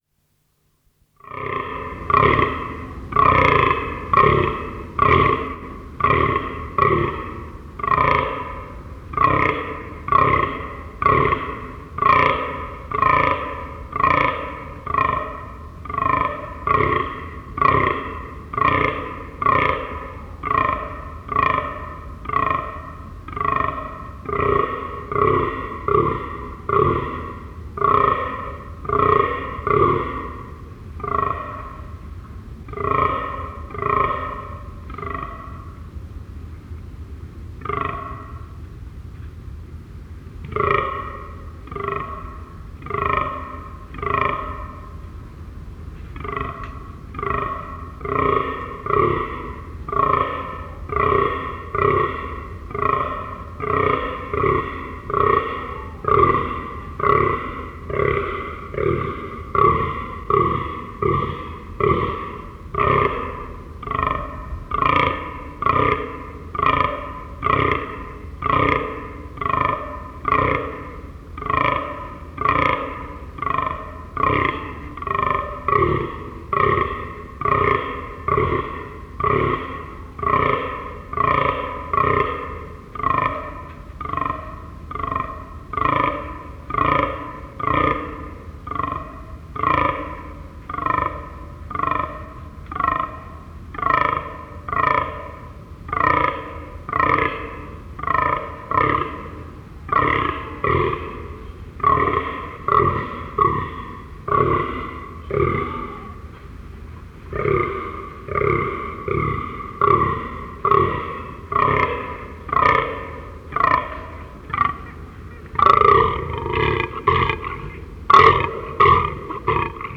Damwild Geräusche
• Brunftzeit: Die Brunftzeit des Damwilds ist im Oktober und November, dabei liefern sich die Männchen beeindruckende Kämpfe und geben laute Rufe von sich.
Damwild-Geraeusche.wav